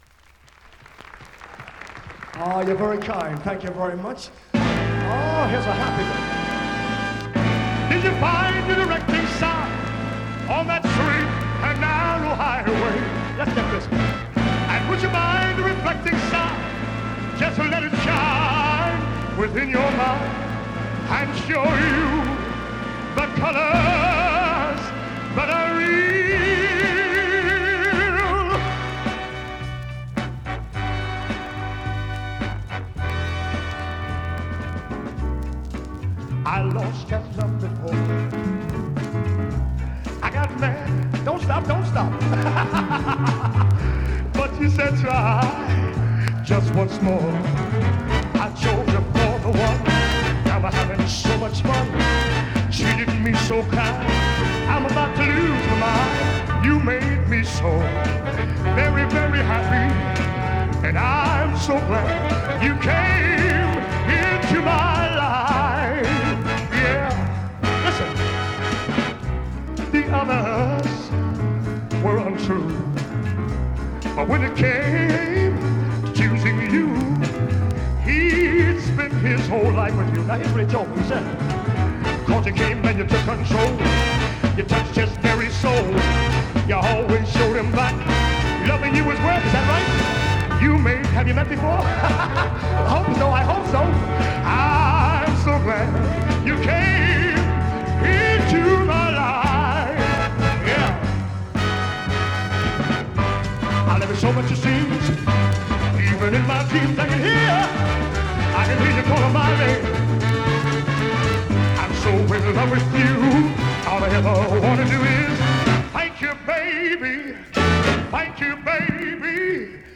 試聴 (実際の出品物からの録音です)